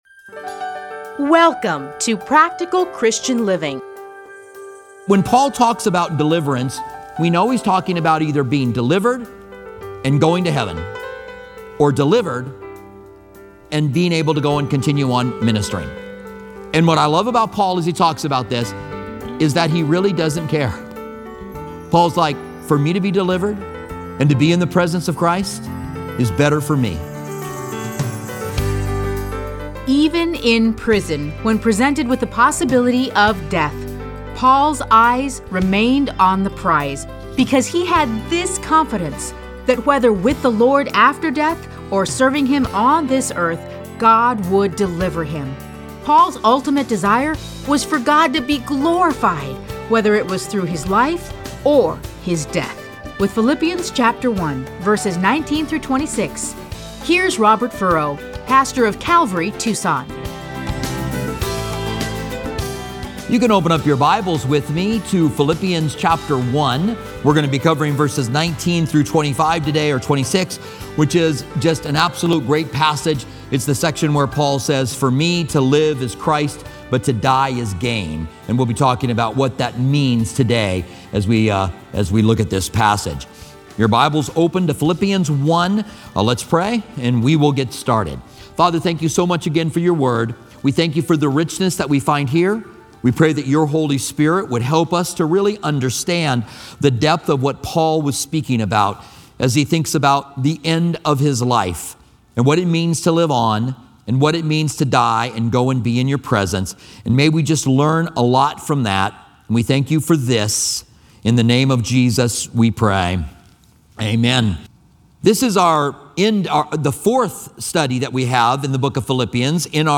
Listen to a teaching from A Study in Philippians 1:19-26.